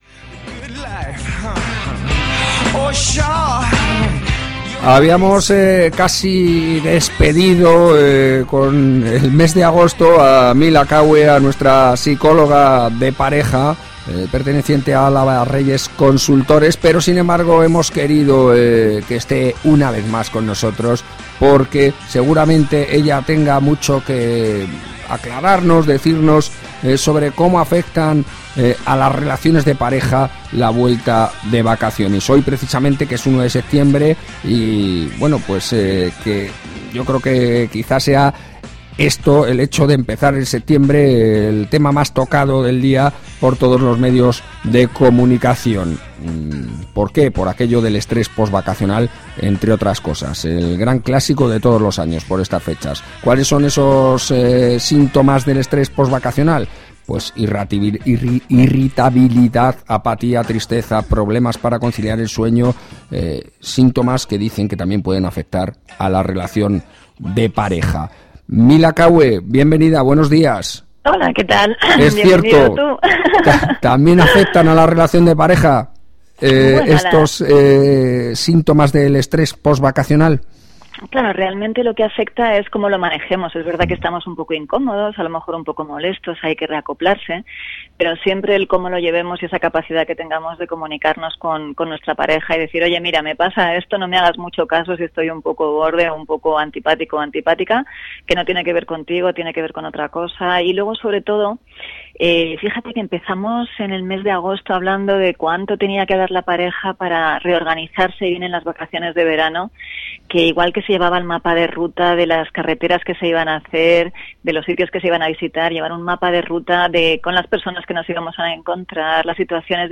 Radio: Podcast de mi intervención en el programa La Singladura de Radio Intereconomía. 1 Septiembre 2014